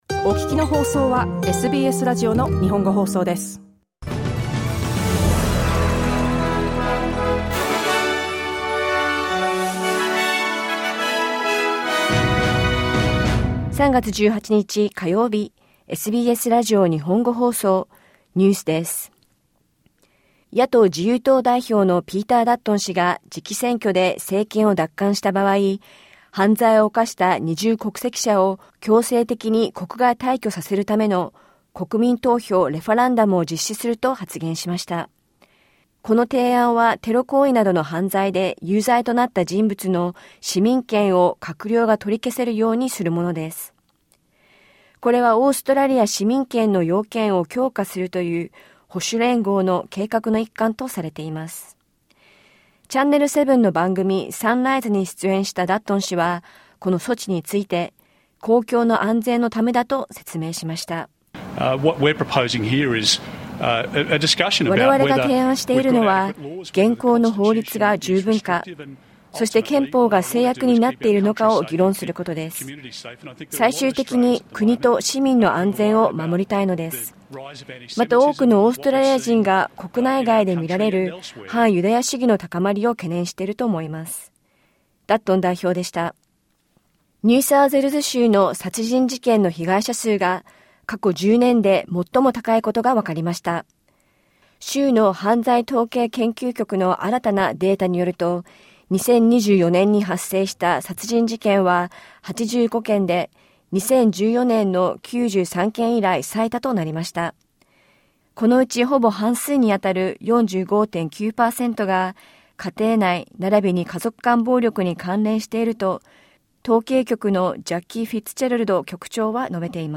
ニューサウスウェールズ州の殺人事件の被害者数が、過去10年で最も高いことがわかりました。午後１時から放送されたラジオ番組のニュース部分をお届けします。